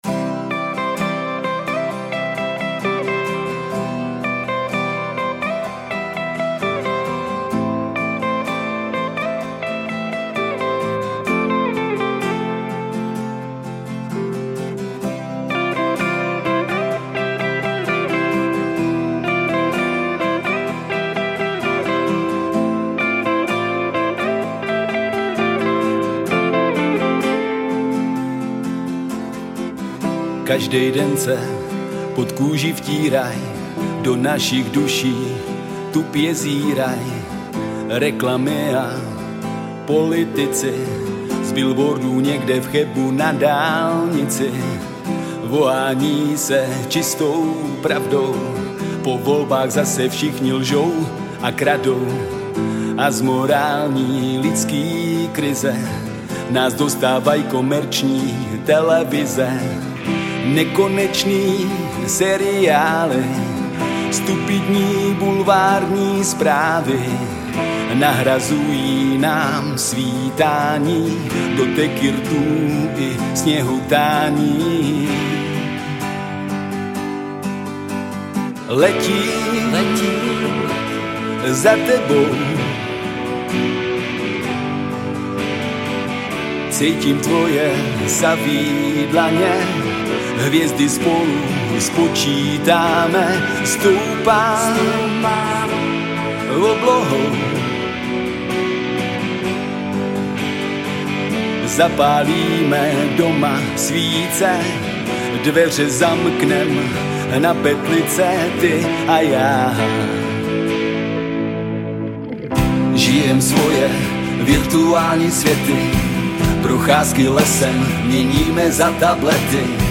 Žánr: Rock
Singl poprockové klubové kapely z Karlových Varů.